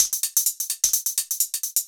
Index of /musicradar/ultimate-hihat-samples/128bpm
UHH_ElectroHatC_128-03.wav